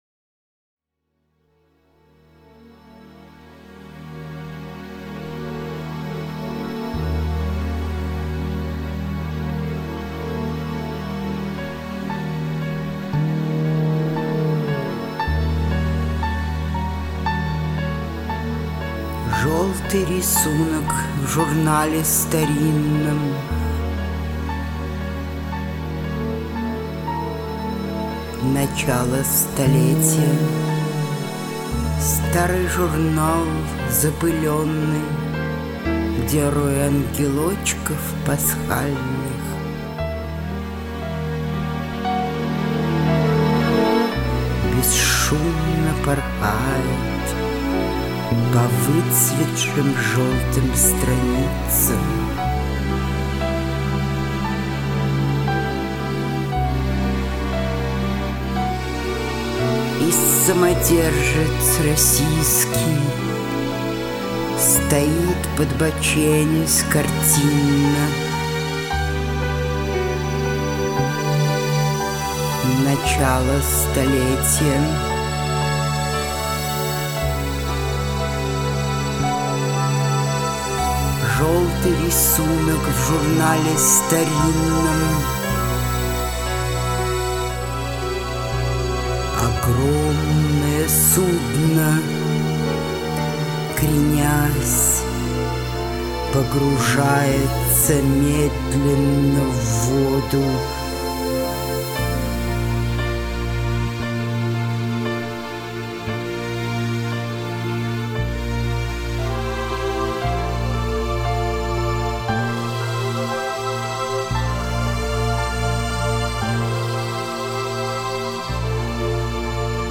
Пронизывающая  дкекламация-песня.